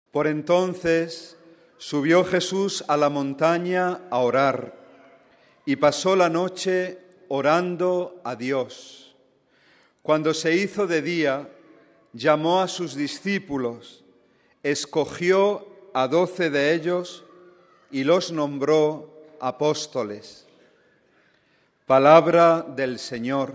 Miércoles, 30 nov (RV).- Miércoles, 30 nov (RV).- El Santo Padre Benedicto XVI celebró esta mañana a las 10,30 su tradicional audiencia general, en el Aula Pablo VI del Vaticano, ante la presencia de varios miles de fieles y peregrinos de numerosos países.
A modo de introducción de esta audiencia general del Santo Padre se leyó la cita tomada del Evangelio según San Lucas: RealAudio